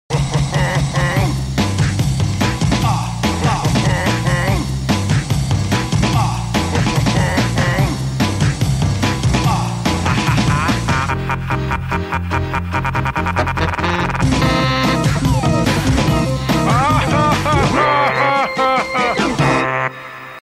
звук заставки